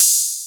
• Ride Sample G Key 02.wav
Royality free drum ride tuned to the G note. Loudest frequency: 7805Hz
ride-sample-g-key-02-Cw3.wav